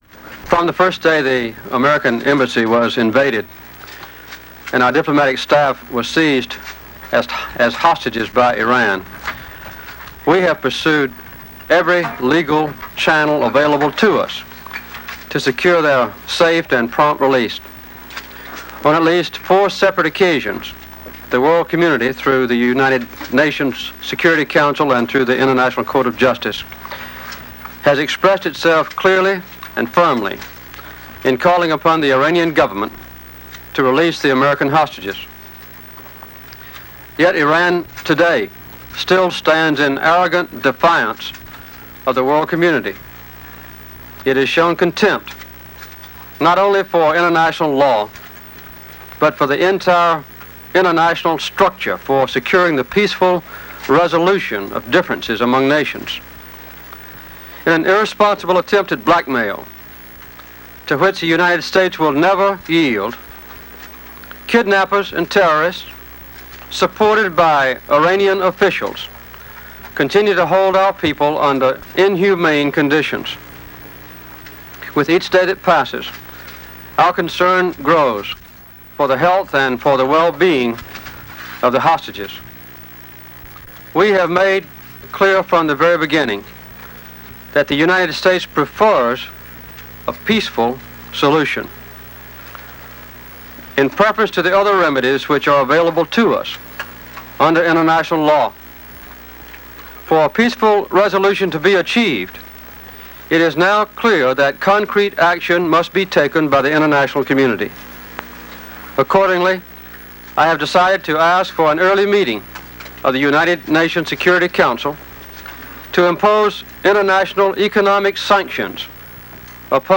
Broadcast on ABC-TV, December 21, 1979.